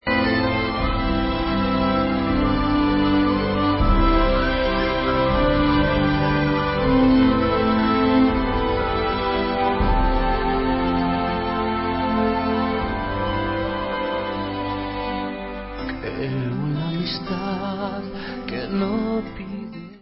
sledovat novinky v kategorii Rock
sledovat novinky v oddělení Heavy Metal